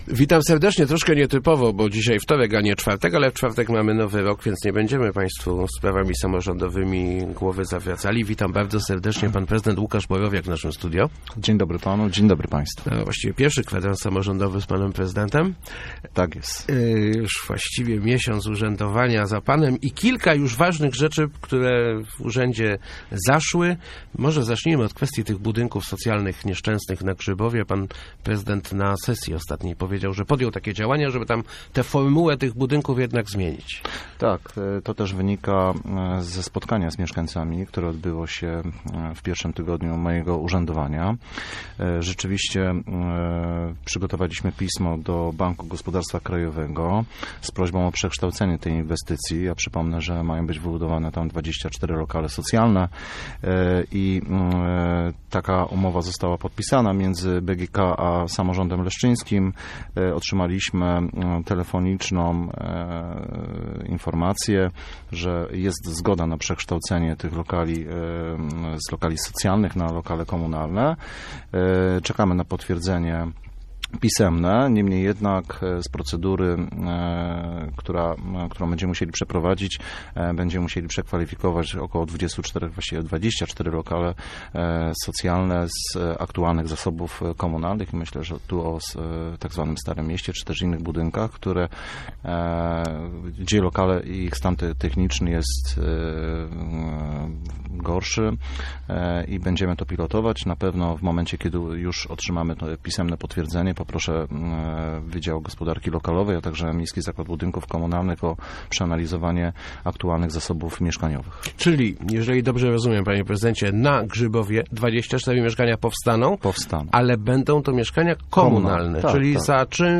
Gościem Kwadransa był prezydent Łukasz Borowiak ...